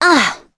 Gremory-Vox_Damage_01.wav